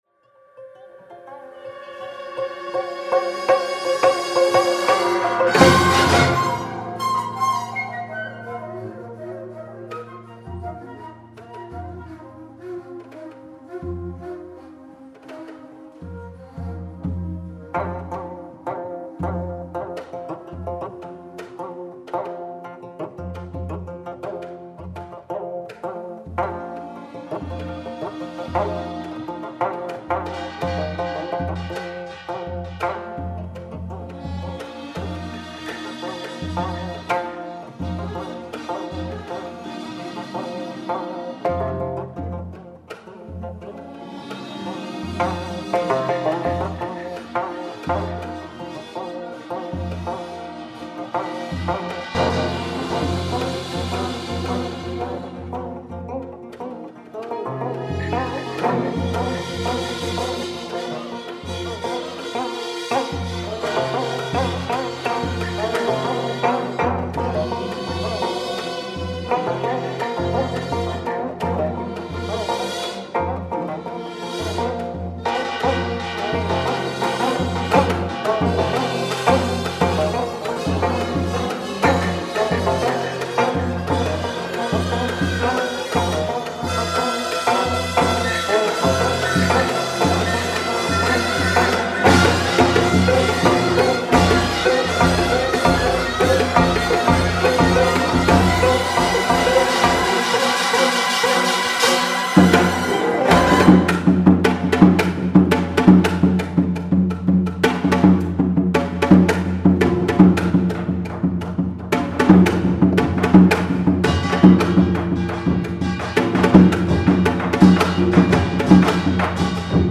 gayageum concerto